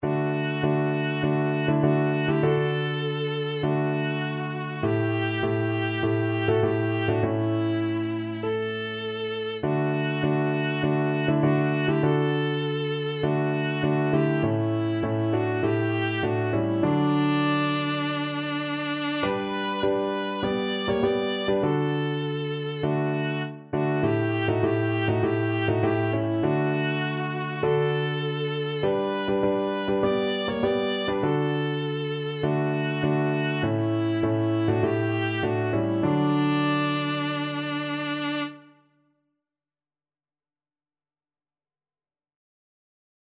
Christian Christian Viola Sheet Music Shall We Gather by the River
Viola
4/4 (View more 4/4 Music)
D major (Sounding Pitch) (View more D major Music for Viola )
Traditional (View more Traditional Viola Music)